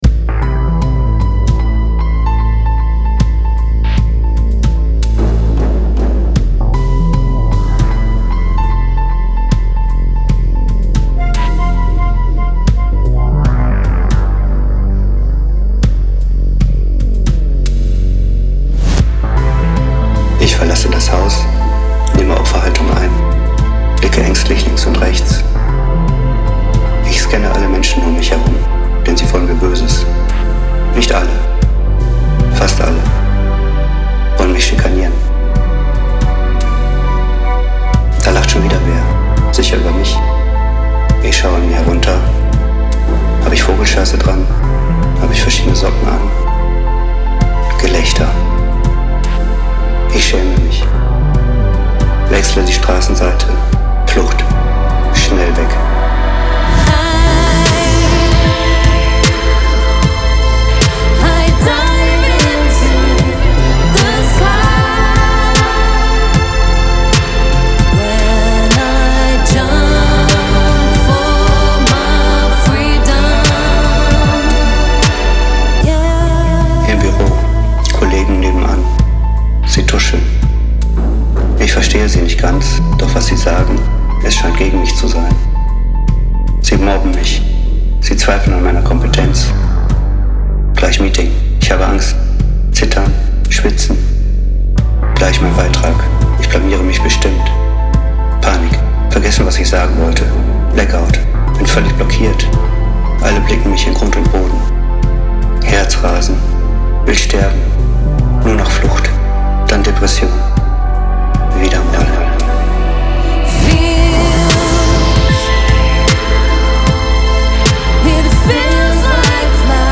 Vocal performance anonymous (chorus / Refrain)